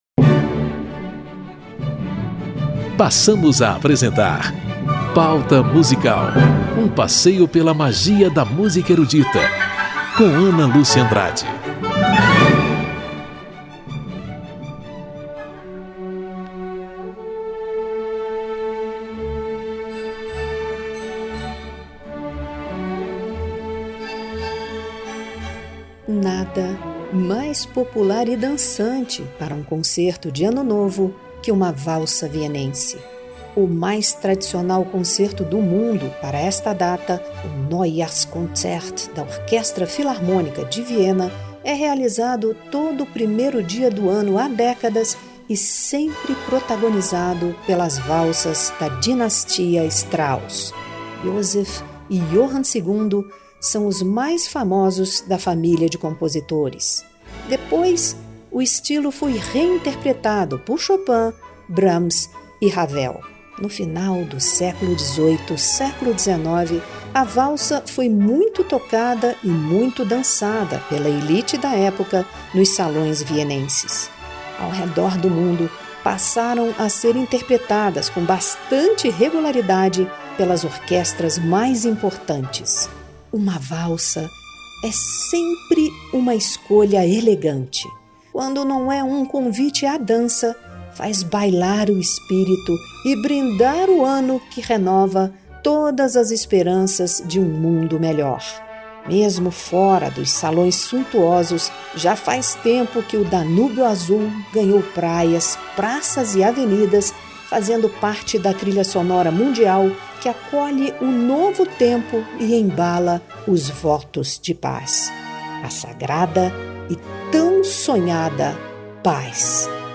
Um brinde ao novo ano! A música de Händel para os Reais Fogos de Artifício e o tradicional estilo vienense das valsas de Strauss, para celebrar a paz e as boas entradas.